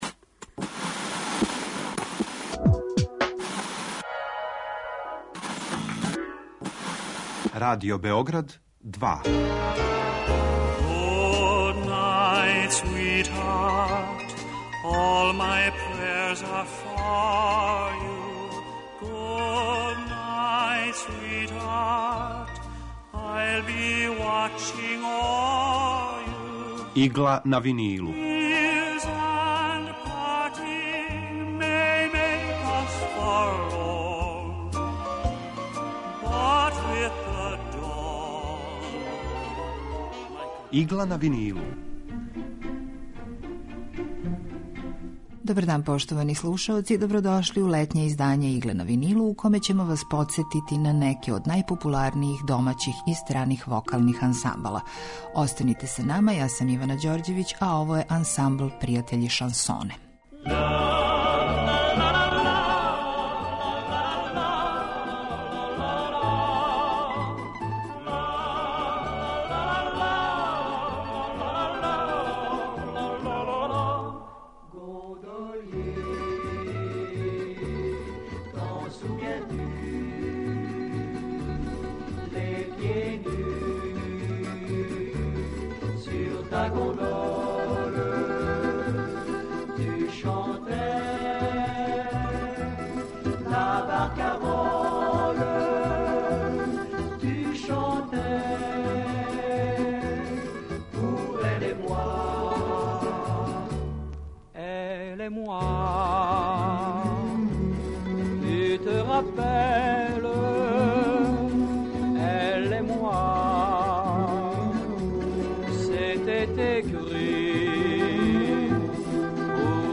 Емисија евергрин музике